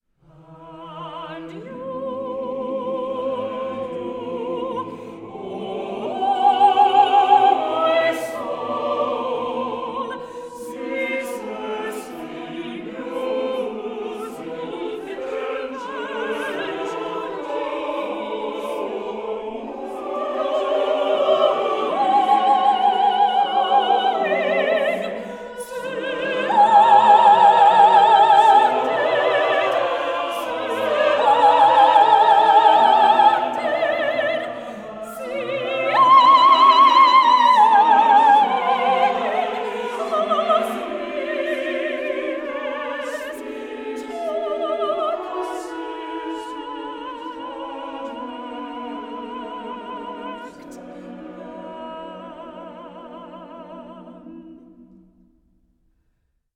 24 bit digital recording
soprano